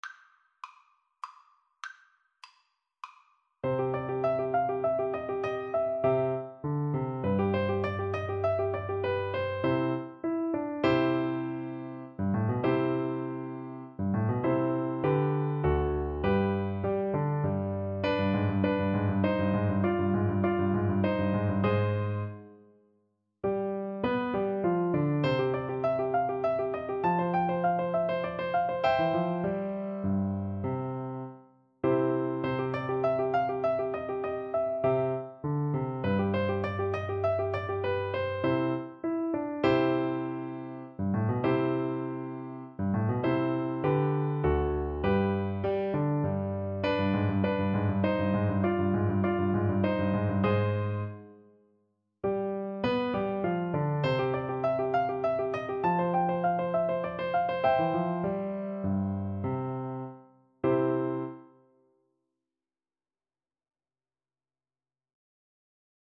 • Unlimited playalong tracks
3/4 (View more 3/4 Music)
Classical (View more Classical Recorder Music)